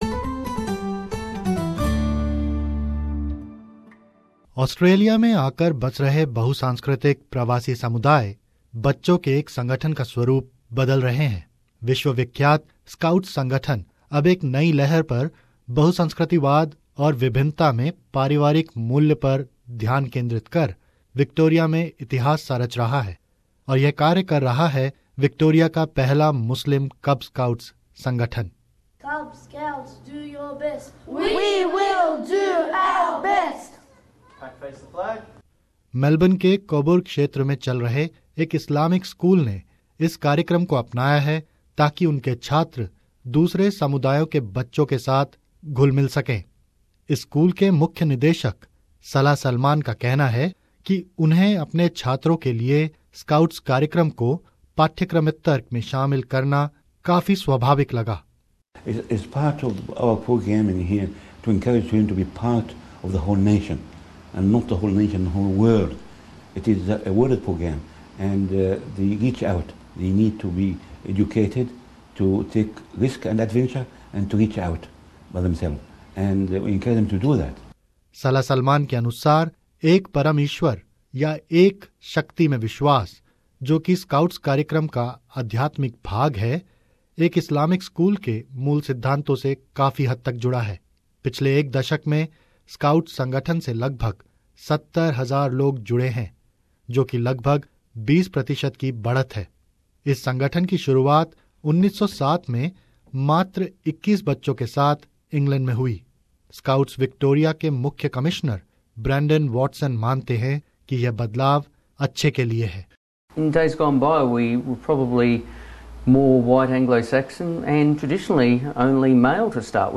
(Sounds of Scouting vows ... fade under) It is a promise made by Victoria's first all-Muslim Cubs Scout group.